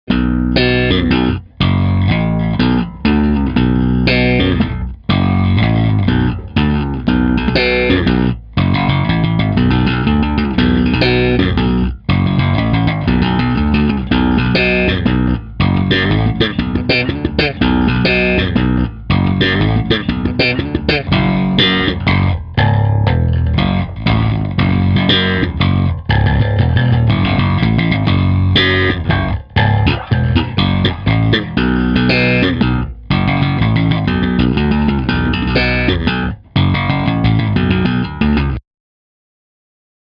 Je to pořízené přes iPad v GarageBandu přes Clean Combo.
Slapová variace (vč. posunu na H strunu)